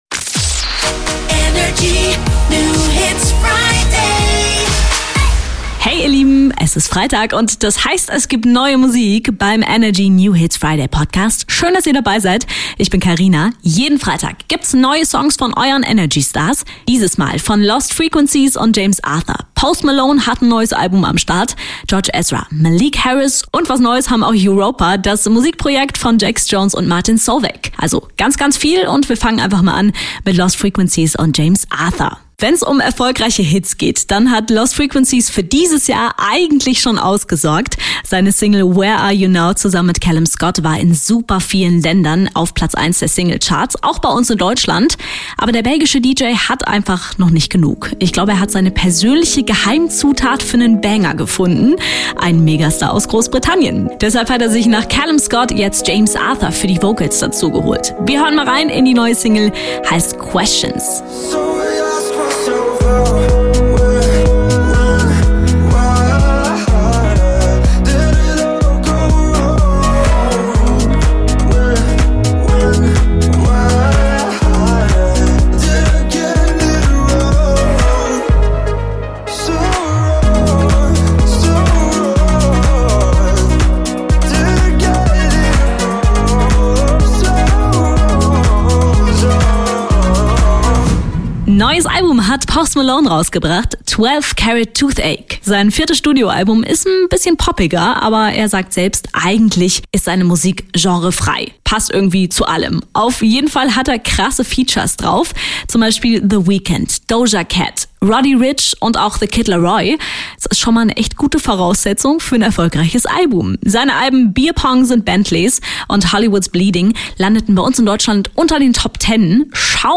stellt brandneue hitverdächtige Songs vor.